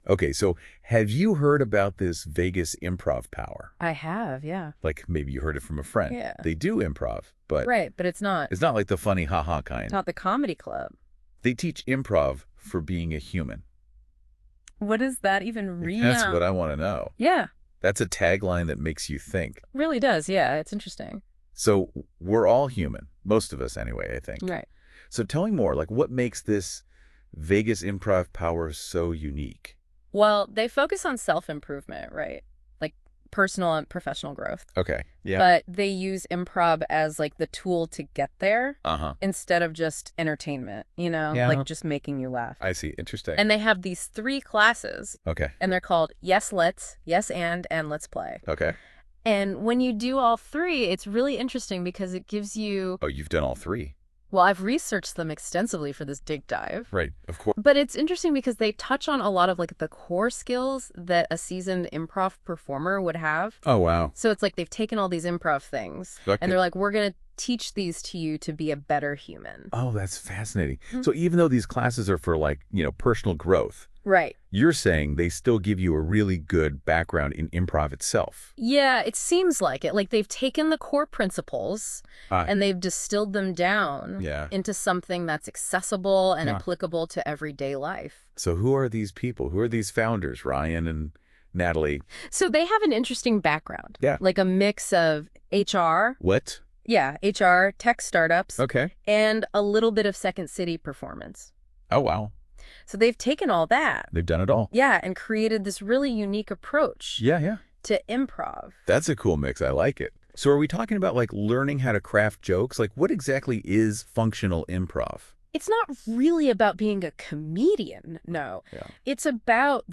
It is 100% AI-generated based on our company background, class descriptions and goals, instructor bios, and previous student feedback.
Check it out, if you don’t mind listening to some AI (that sounds better than we could do ourselves, to be honest).